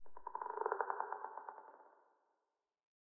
Minecraft Version Minecraft Version 1.21.5 Latest Release | Latest Snapshot 1.21.5 / assets / minecraft / sounds / block / creaking_heart / hurt / trail1.ogg Compare With Compare With Latest Release | Latest Snapshot